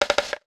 NOTIFICATION_Rattle_05_mono.wav